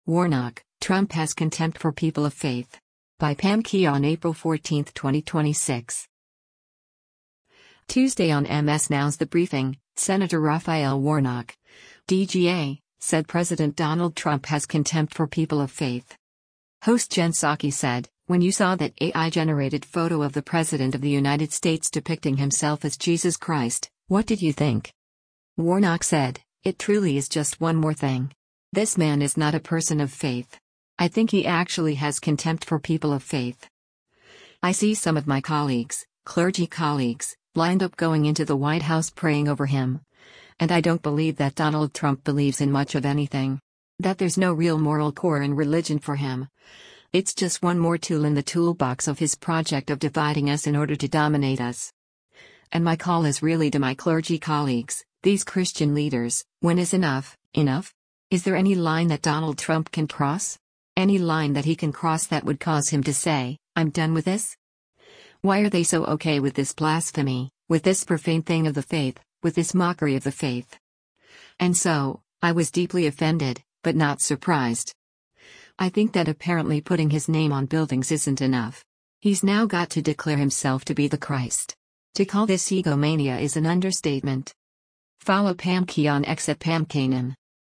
Tuesday on MS NOW’s “The Briefing,” Sen. Raphael Warnock (D-GA) said President Donald Trump “has contempt for people of faith.”
Host Jen Psaki said, “When you saw that AI-generated photo of the President of the United States depicting himself as Jesus Christ, what did you think?”